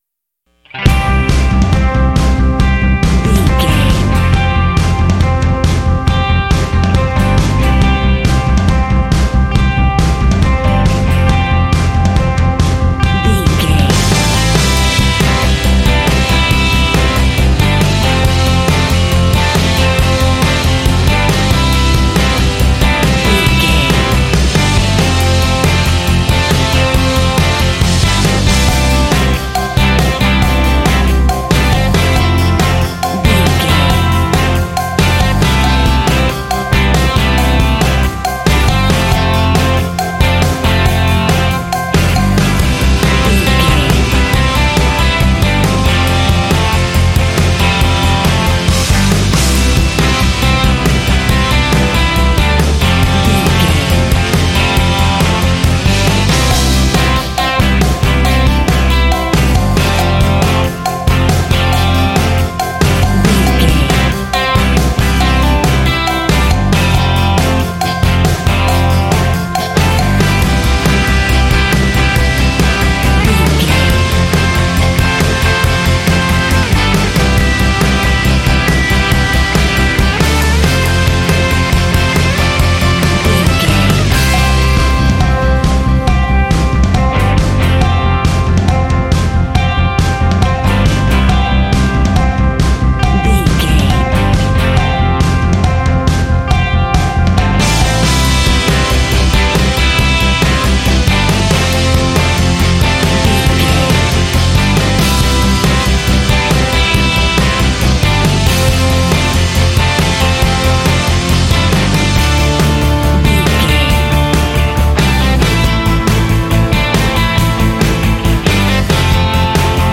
Ionian/Major
energetic
uplifting
drums
electric guitar
bass guitar